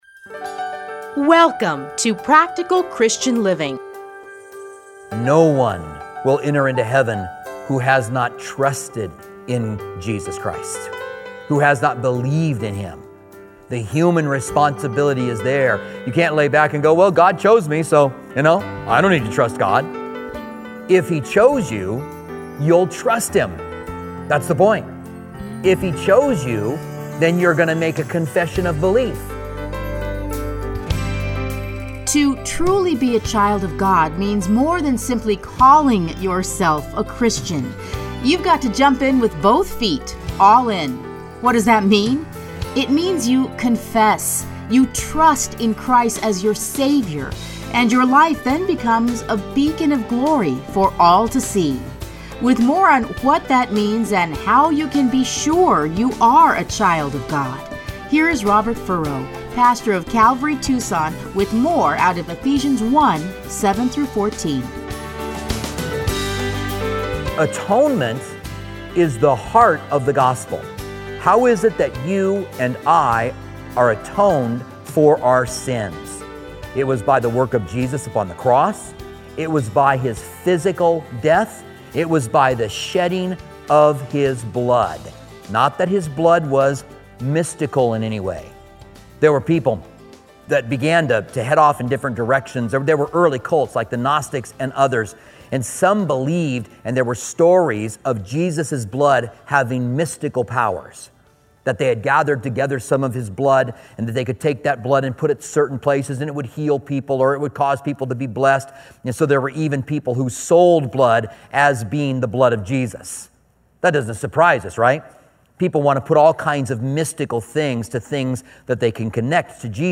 Playlists Commentary on Ephesians Download Audio